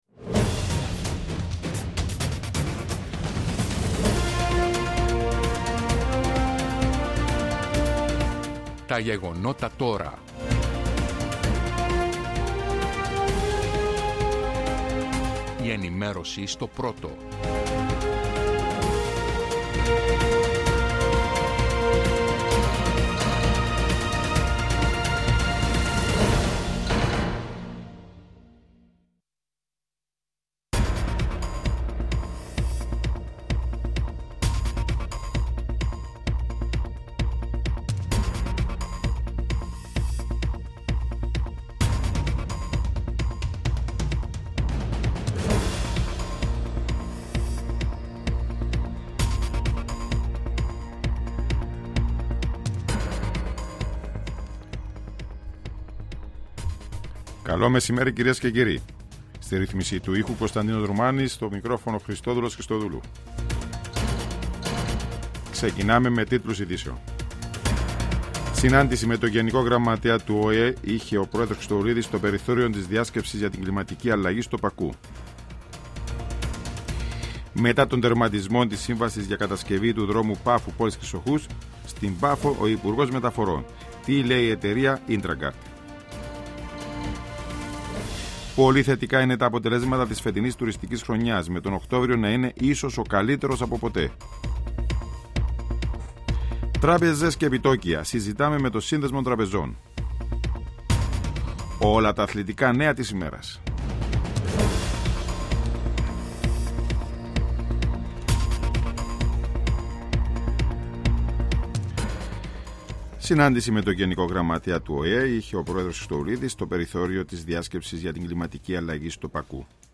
Η επικαιρότητα της ημέρας αναλυτικά με ρεπορτάζ, συνεντεύξεις και ανταποκρίσεις από Κύπρο και εξωτερικό.